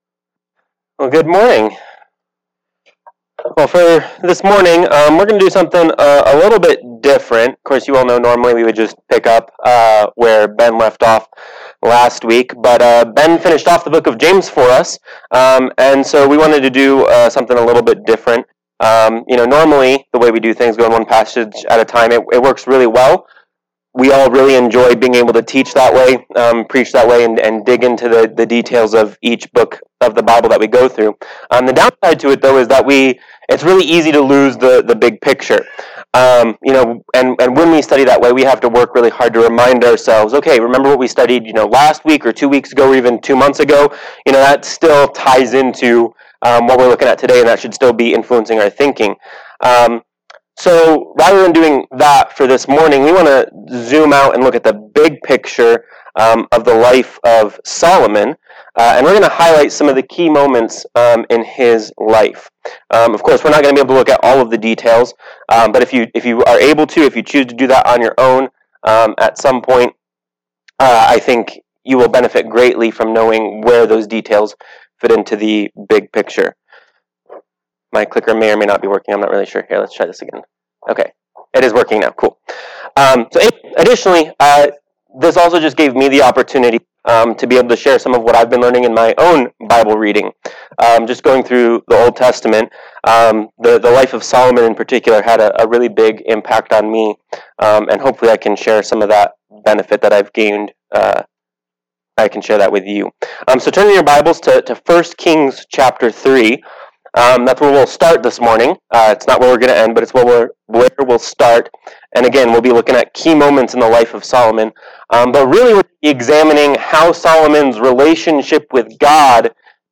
Passage: 1 Kings 3, 8, 11 Service Type: Sunday Morning Worship
Solomon-Sermon.mp3